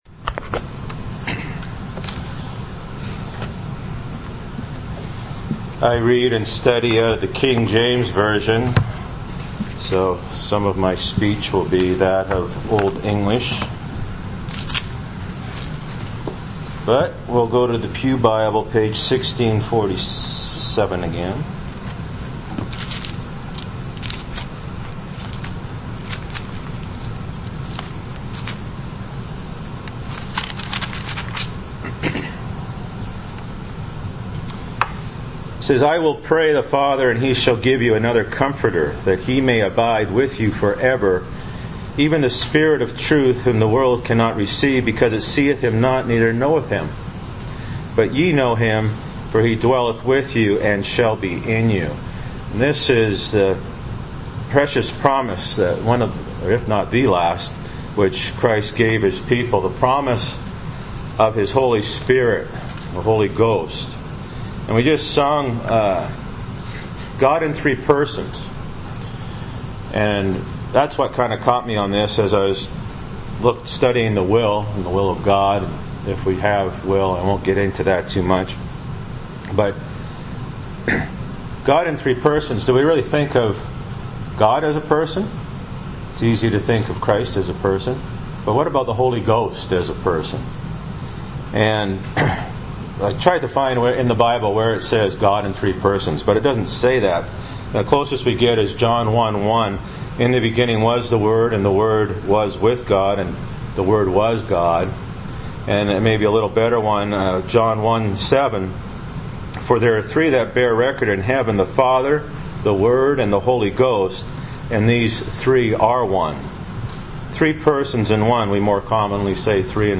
John 14:16-17 Service Type: Sunday Morning Bible Text